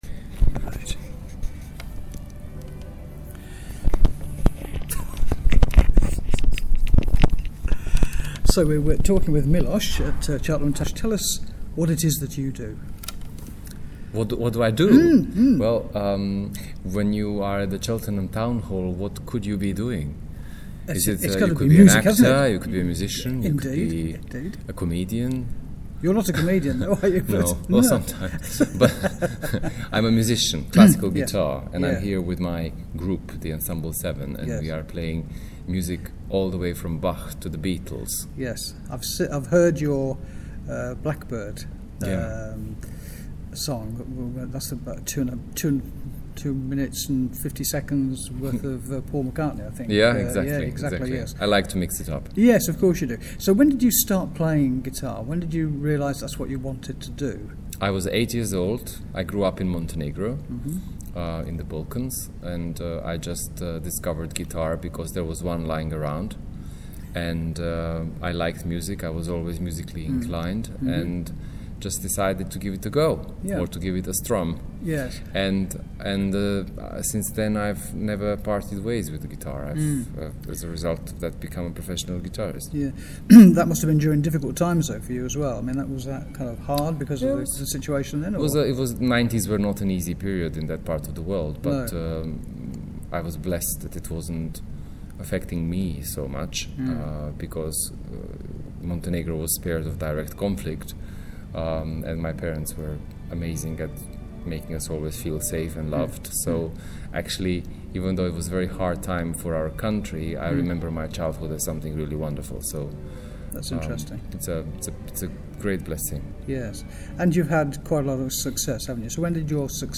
Events and Shows, In The Cooler, Interviews
“In The Cooler” was able to have a few words with Miloš just before his Cheltenham Town Hall Concert….(with Ensemble 7)